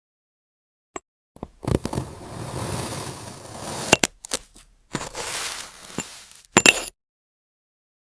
ASMR | Porcelain Dollhouse Slice sound effects free download